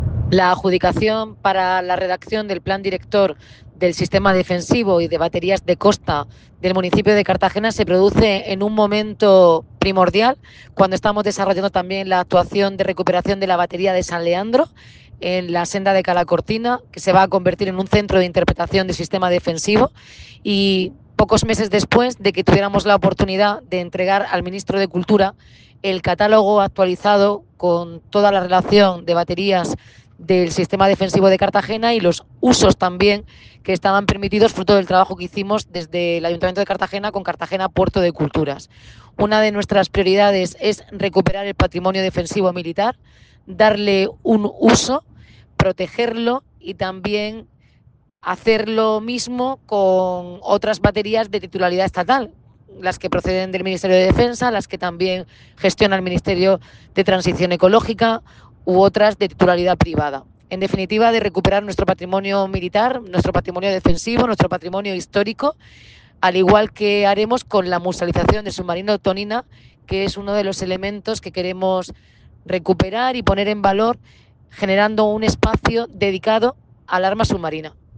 Enlace a Declaraciones de Noelia Arroyo sobre Plan Director para las baterías de costa